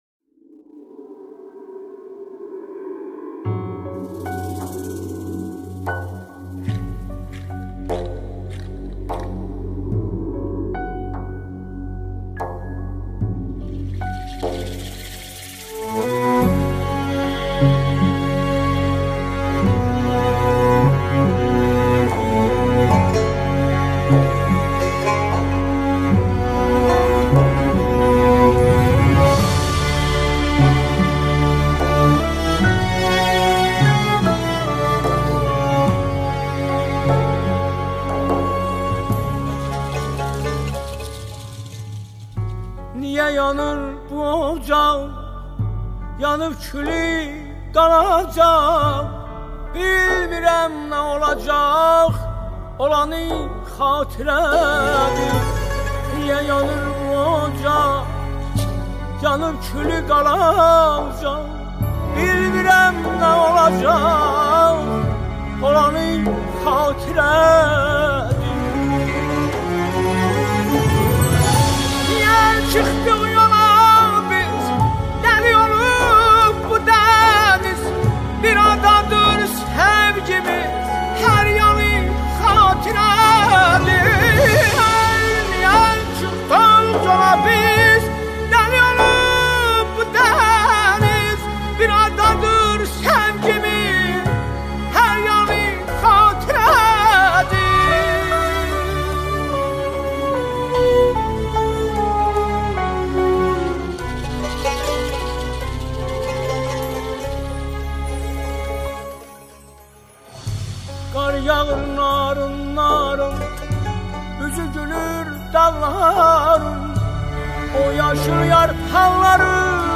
آهنگ آذربایجانی آهنگ غمگین آذربایجانی
دانلود ترانه ترکی